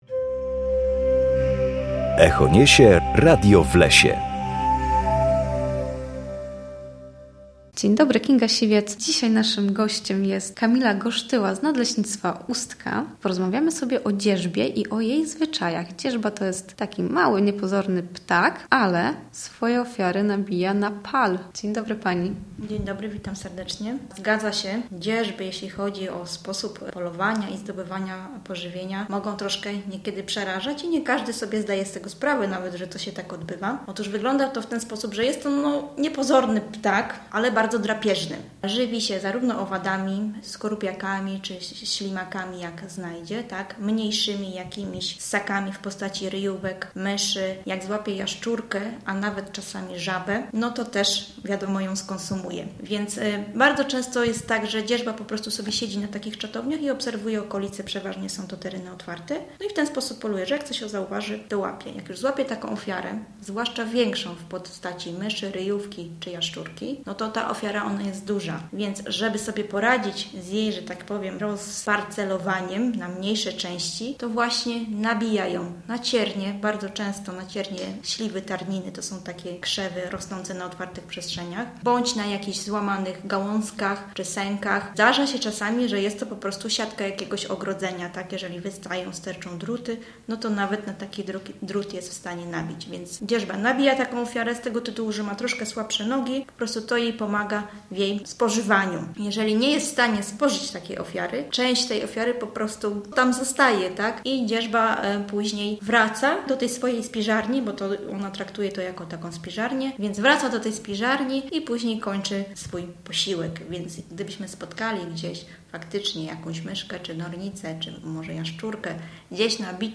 W każdą środę o godzinie 7:20 na antenie Studia Słupsk będziemy rozmawiać o naturze i sprawach z nią związanych.